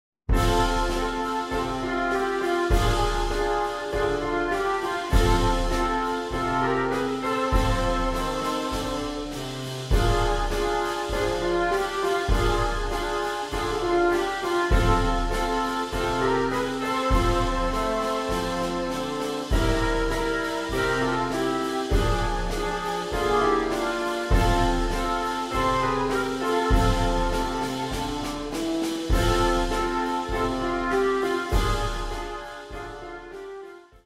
simple twelve bar blues based melodies
Woodwind